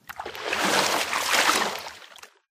slosh.ogg